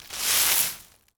Broom Sweeping
sweeping_broom_leaves_stones_16.wav